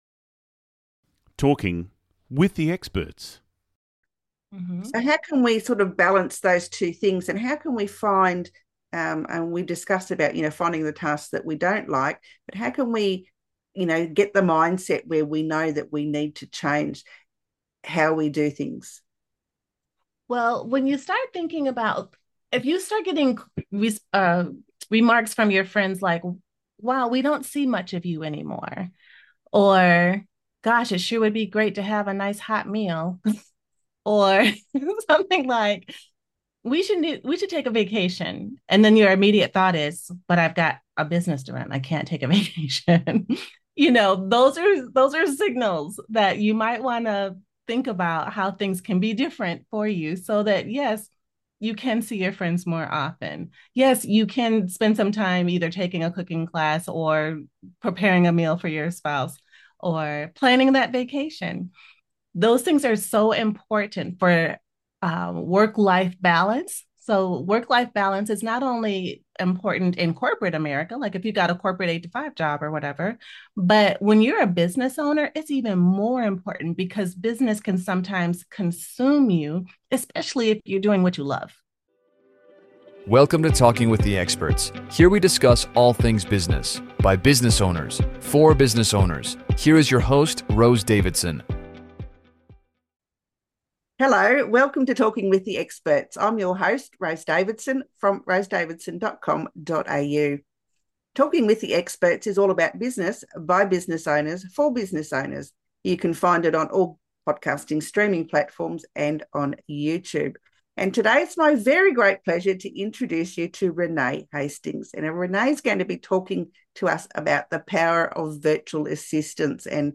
The conversation also delves into leveraging the specialized skills that virtual assistants bring to the table.